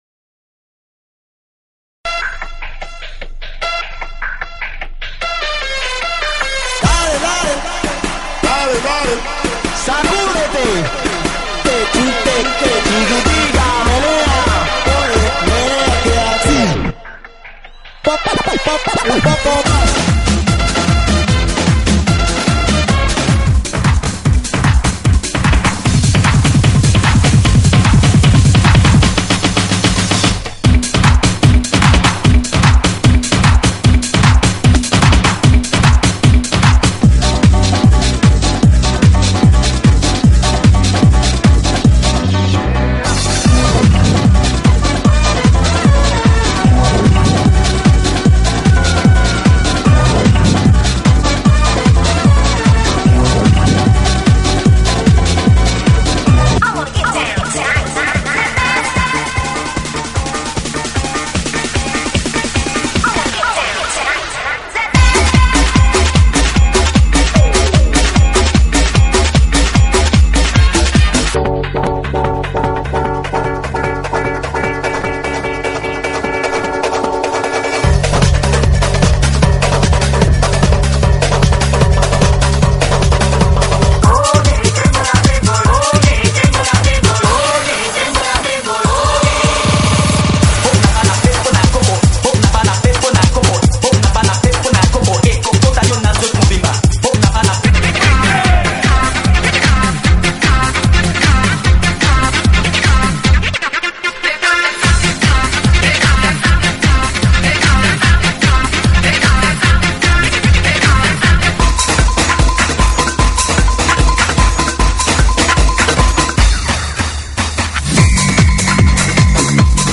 GENERO: ELECTRO – POP